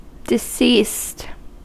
Ääntäminen
UK : IPA : /dɪ.ˈsiːst/